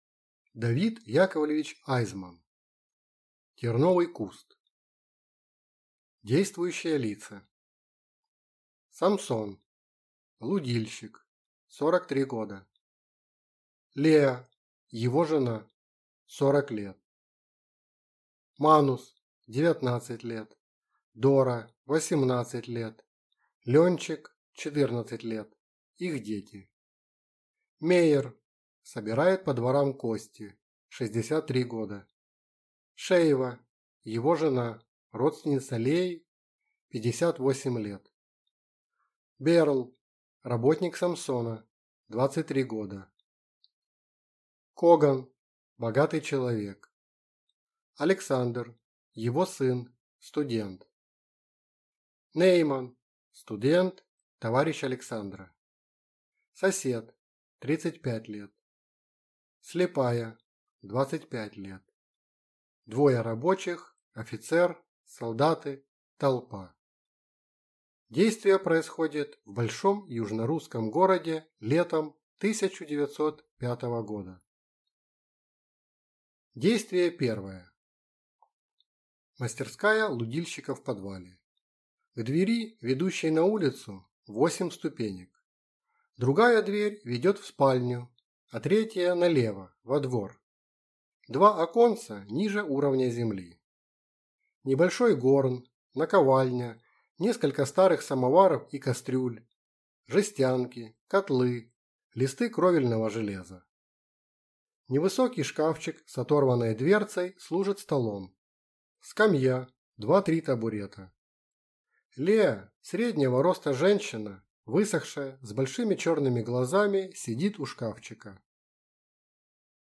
Аудиокнига Терновый куст | Библиотека аудиокниг